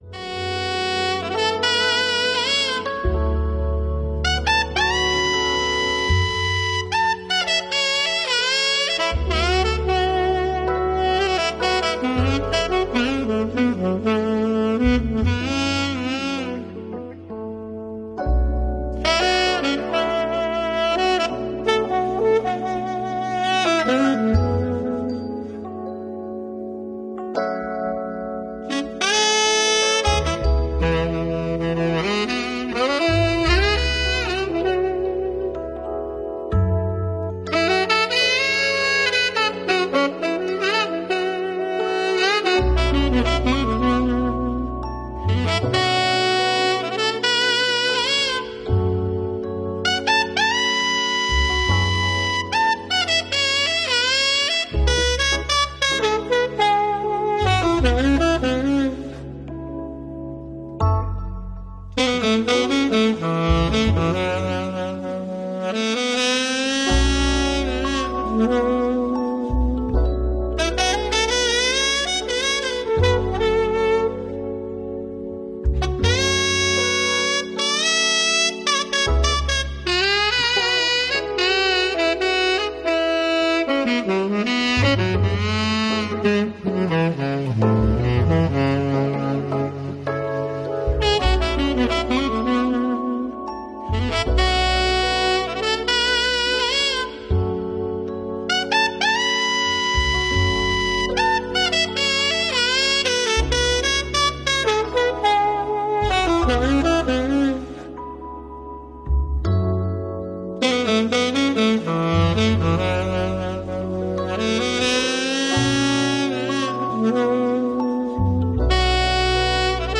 Today we turn to a lighter side of the instrument, exploring short pieces that highlight the many sizes of the saxophone family: soprano, alto, tenor, and baritone, as well as ensemble arrangements.